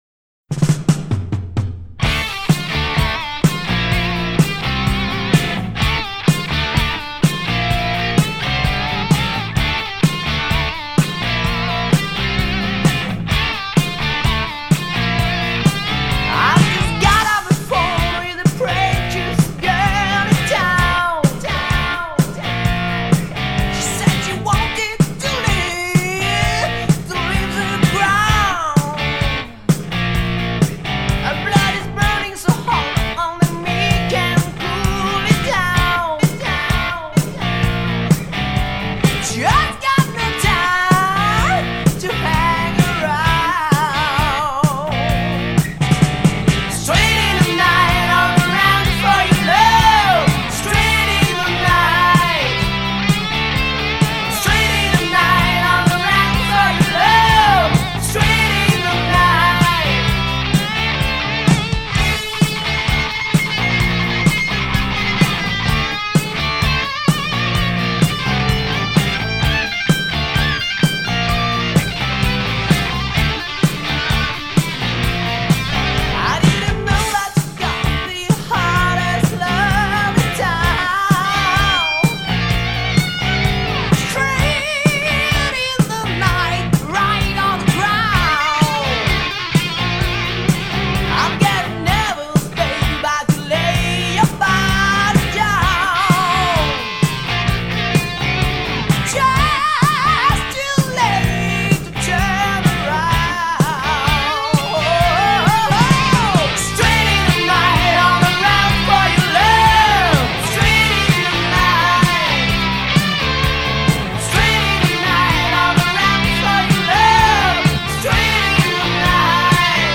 Файл в обменнике2 Myзыкa->Зарубежный рок